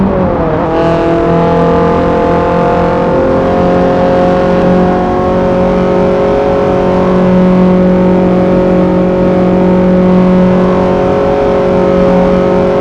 focussvt_gear.wav